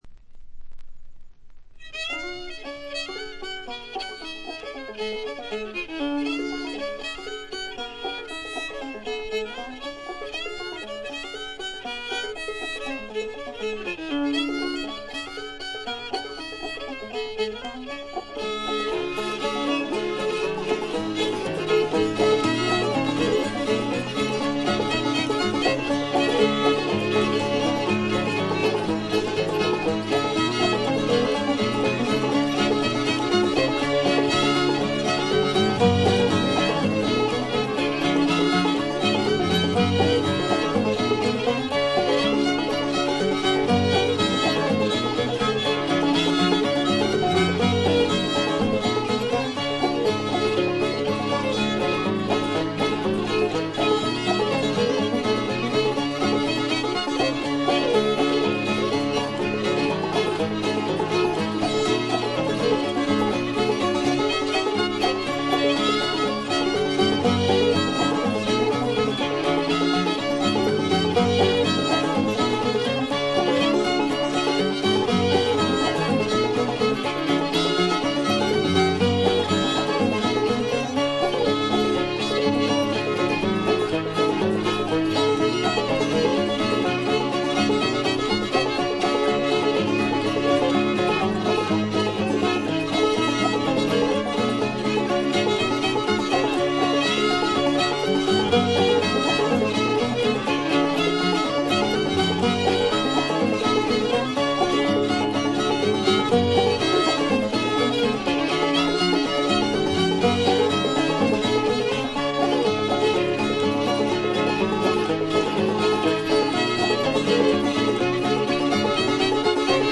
アパラチアの伝承音楽の継承者として注目される存在でした。
試聴曲は現品からの取り込み音源です。
Banjo, Mandolin, Fiddle
Dulcimer
Guitar
Recorded At - Radio WDBS Studios, Durham